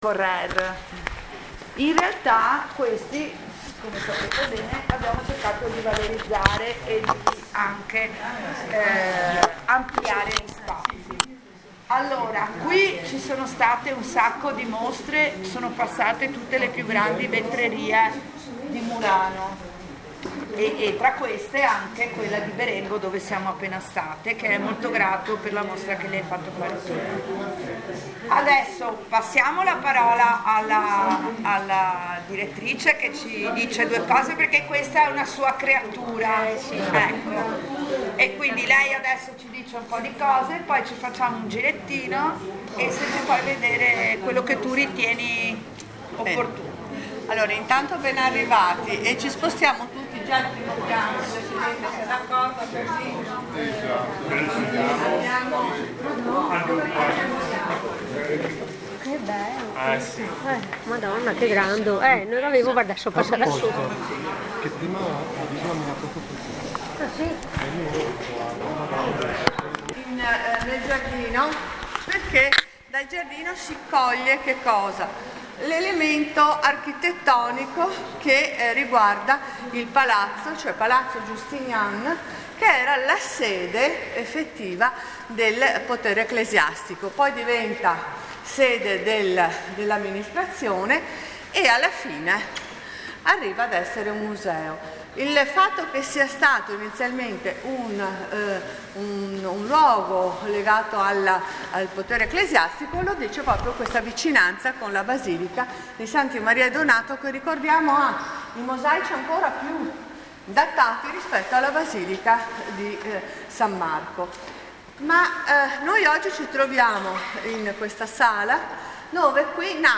alle ore 13 la Presidente Pea verificato il numero legale apre i lavori della commissione